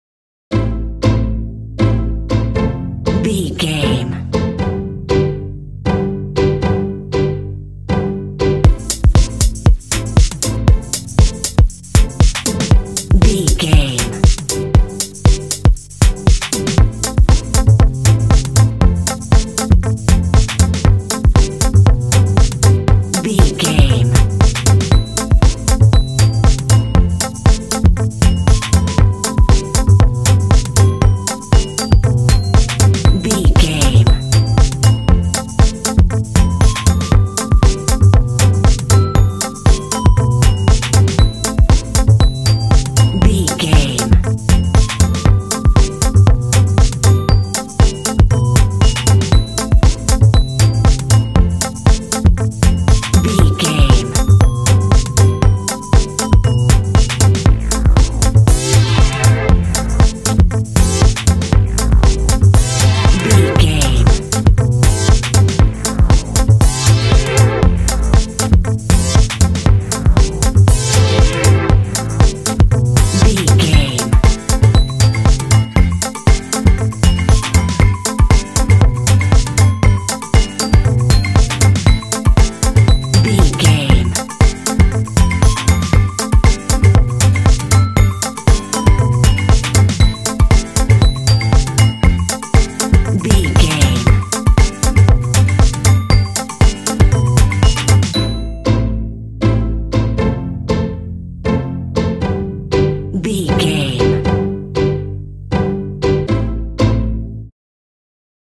This cute track is ideal for underscoring kids games!
Aeolian/Minor
groovy
cool
strings
drums
percussion
synthesiser
electric guitar
bass guitar
contemporary underscore